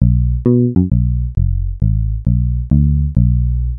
Bass Guitar
GuitarBass.mp3